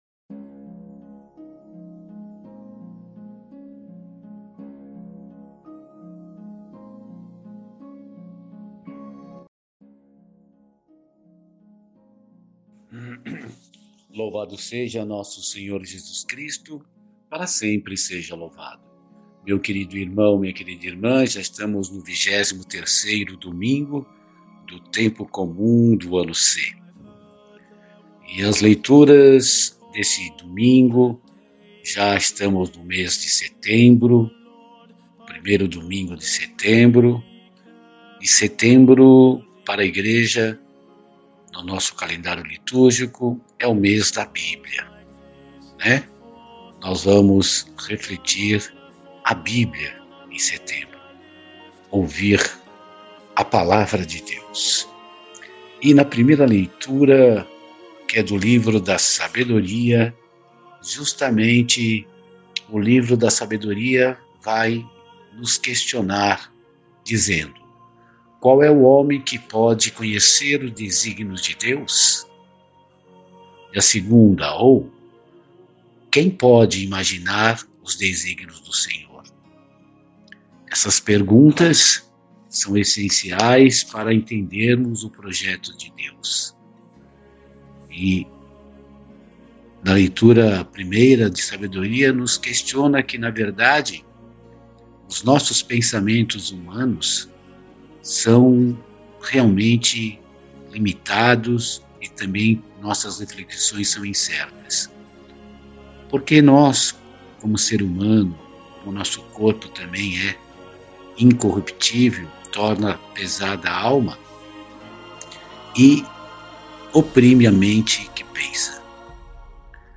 Reflexão e Meditação 23 Domingo Do Tempo Comum -Ano C